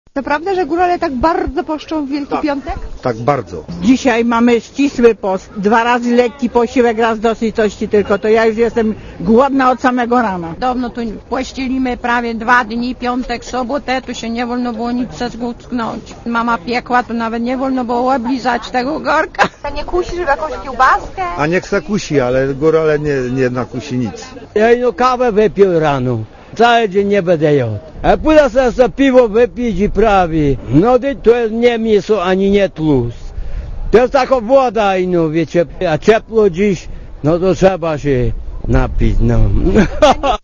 Posłuchaj co górale mówia o poście (157 KB)